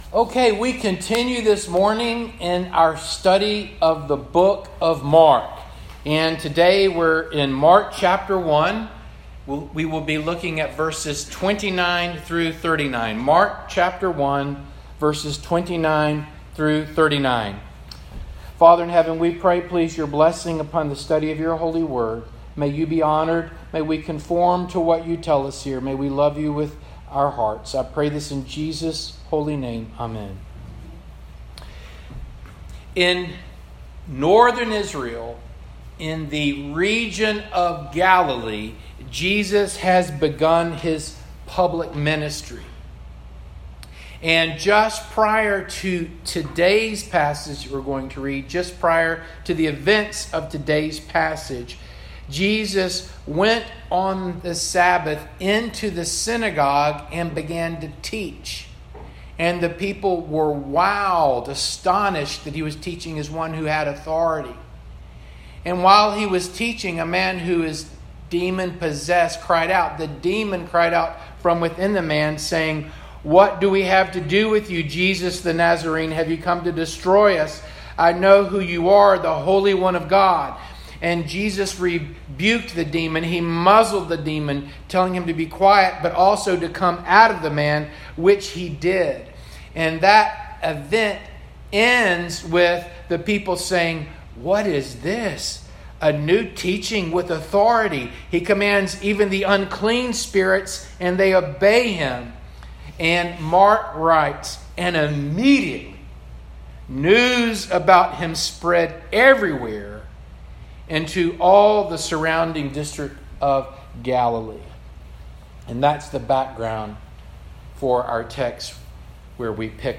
Mark Passage: Mark 1:29-39 Service Type: Morning Service Download Files Bulletin « “Jesus Said to Them